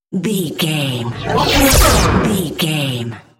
Whoosh to hit engine speed
Sound Effects
Atonal
futuristic
intense
tension
woosh to hit